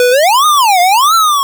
retro_synth_wobble_05.wav